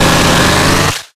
Audio / SE / Cries / BEEDRILL.ogg
BEEDRILL.ogg